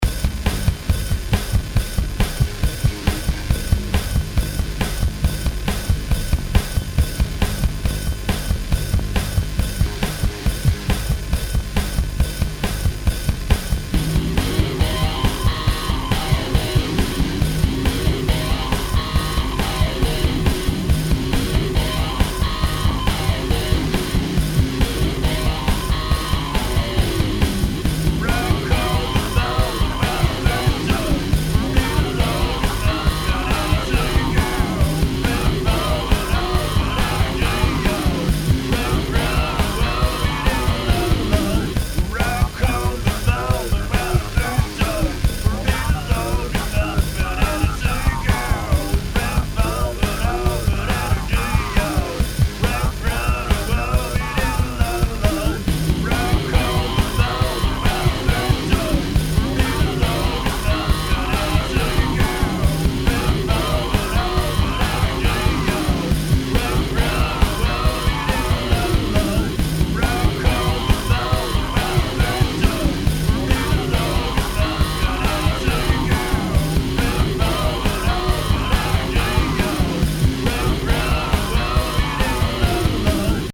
home of the daily improvised booty and machines -
bass grooves